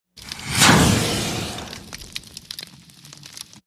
Gunpowder|Exterior | Sneak On The Lot
FIRE GUNPOWDER: EXT: Flare of gunpowder thrown on fire, crackling fire at end.. Fire Burn.